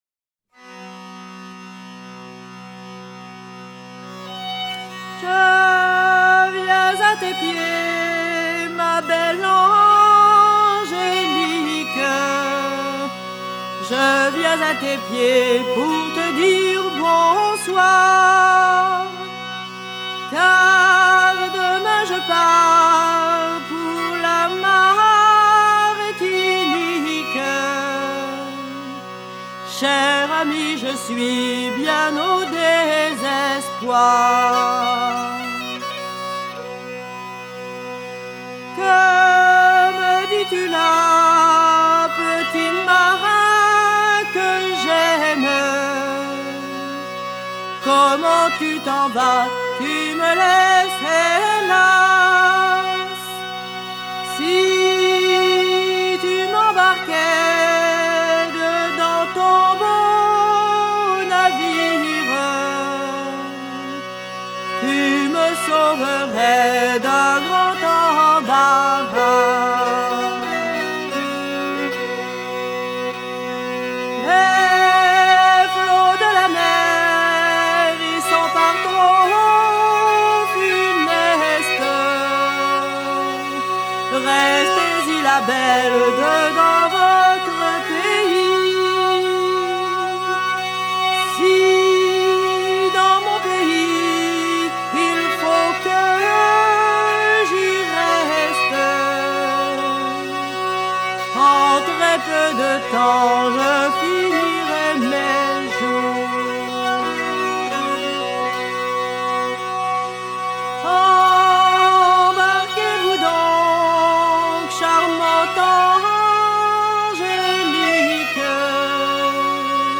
Version recueillie en 1969
Genre strophique